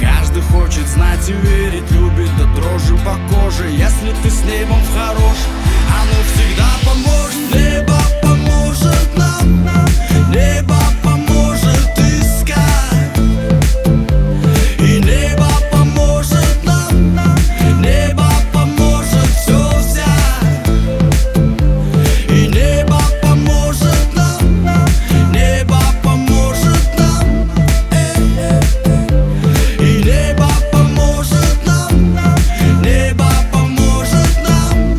Pop Hip-Hop Rap